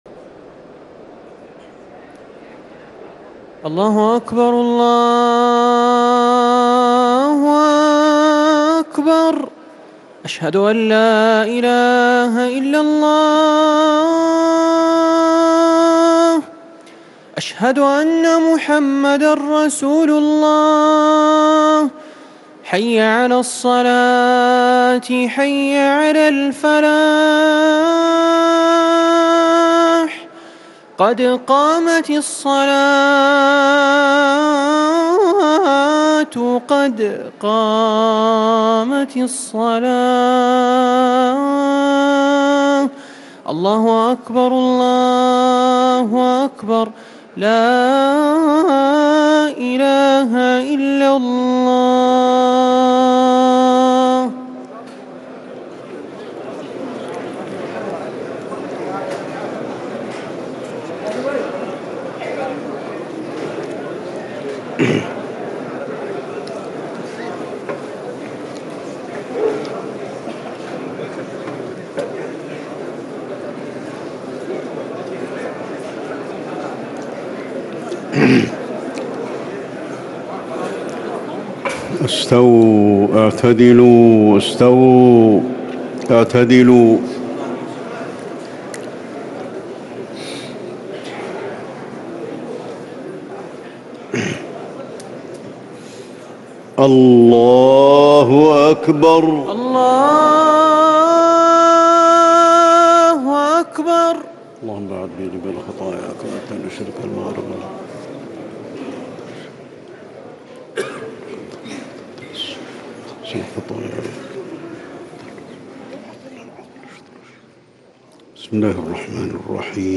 صلاة المغرب 1-9-1440هـ من سورتي البقرة - التوبة | mghrip 6-5-2019 prayer from Surah Al-Baqara and At-Tawba > 1440 🕌 > الفروض - تلاوات الحرمين